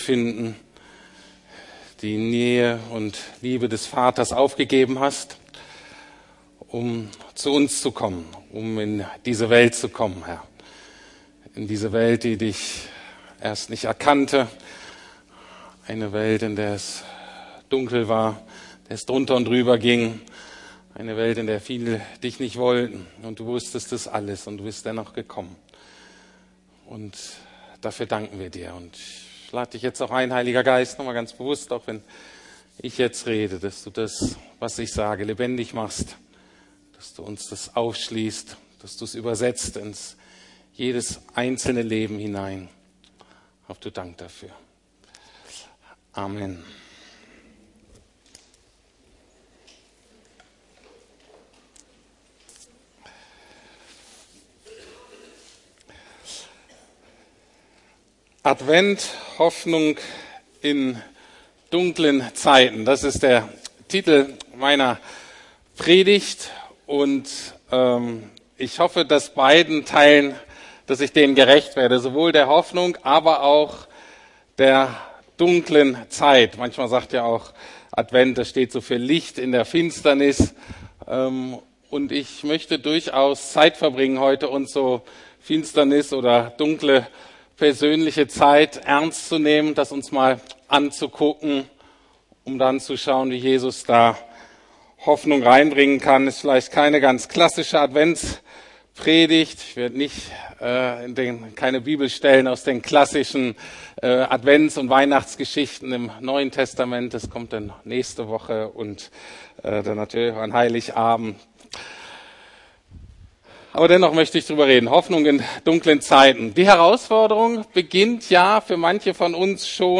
Advent - Hoffnung in dunklen Zeiten ~ Predigten der LUKAS GEMEINDE Podcast